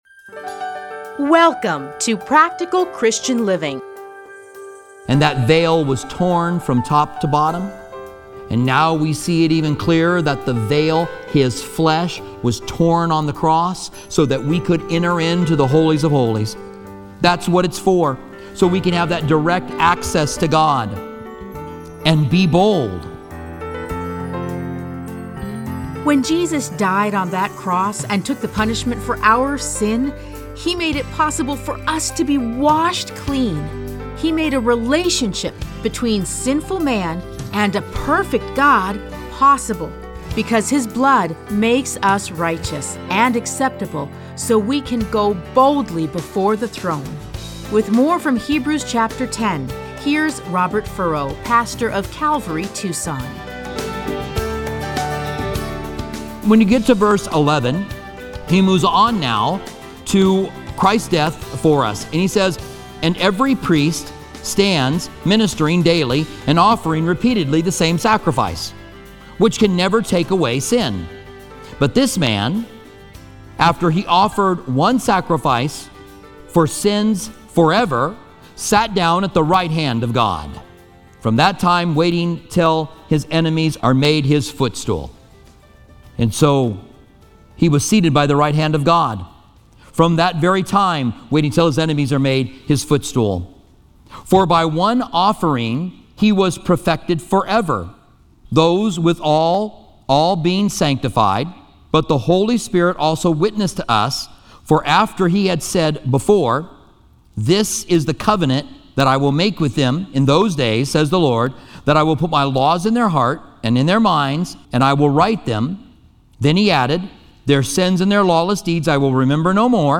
Listen to a teaching from Hebrews 10:1-39.